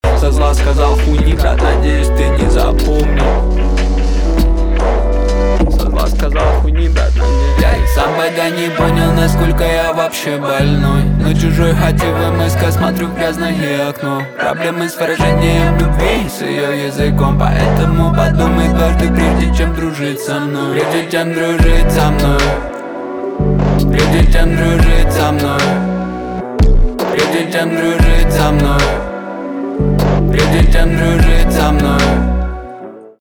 русский рэп , пианино
басы